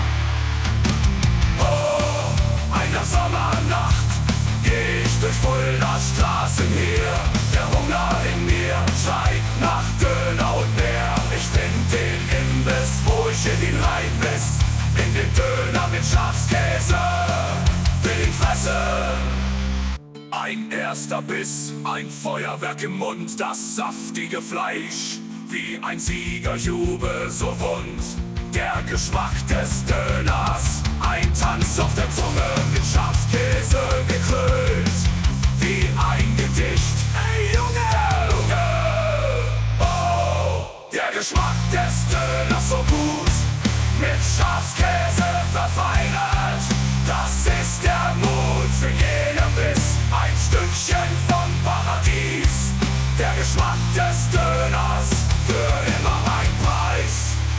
Hört sich zwar nicht so wirklich realistisch an aber ist sehr lustig